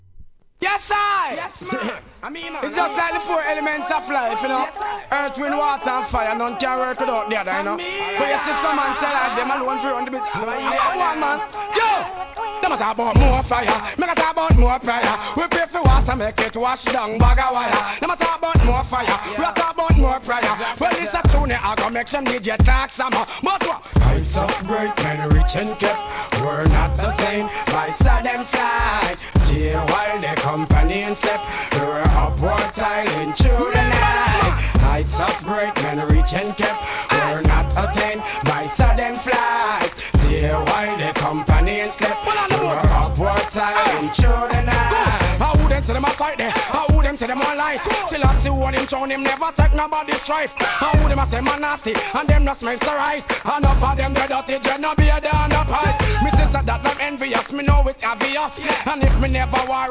REGGAE, R&Bブレンド物!!